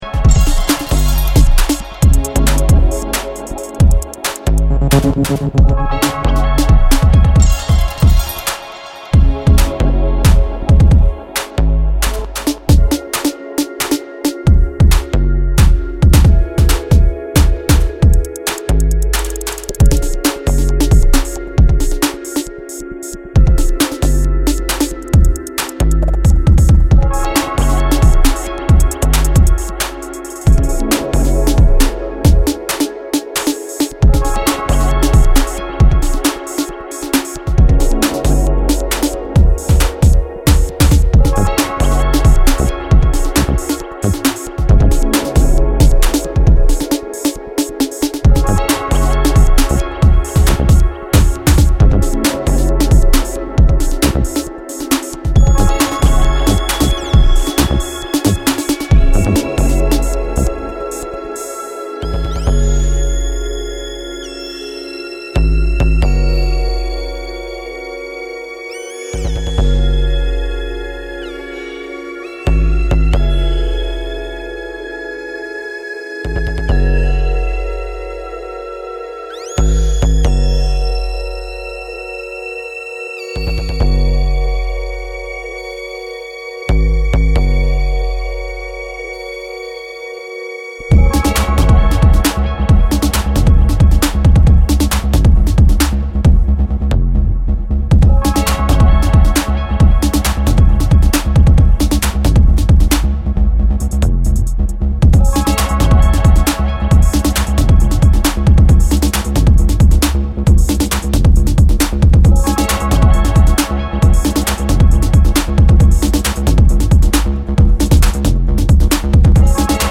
エモーショナルな躍動感に溢れた未来的エレクトロの傑作が軒を連ねる好作品